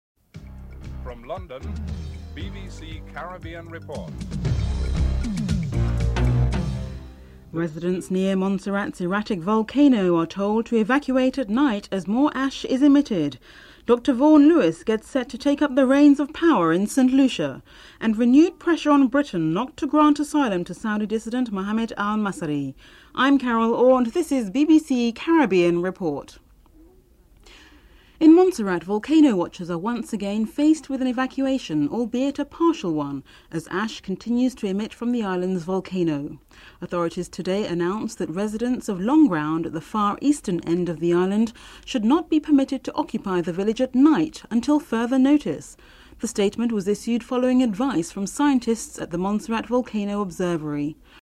1. Headlines (00:00-00:27)
Former Prime Minister John Compton is interviewed (02:20-03:18)